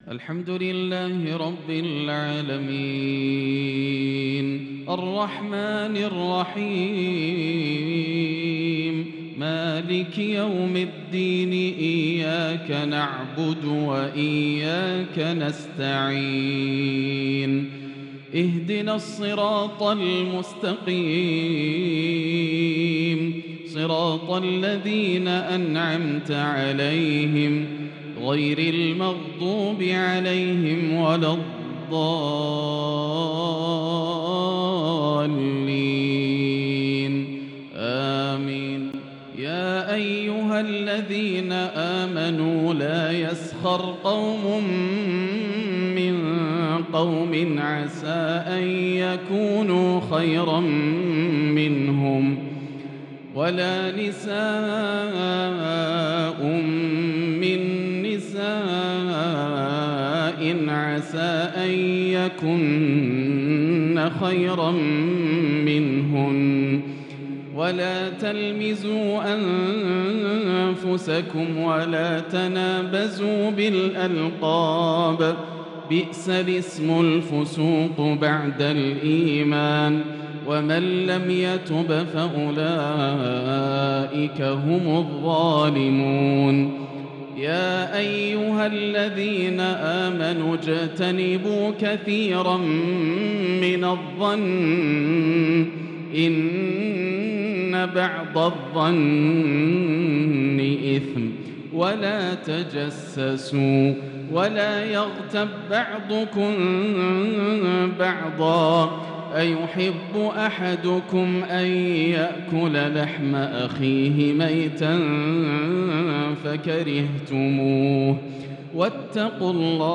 عشاء الأربعاء 8-7-1443هـ من سورة الحجرات |lsha prayer from Surah Al-Hujraat 9-2-2022 > 1443 🕋 > الفروض - تلاوات الحرمين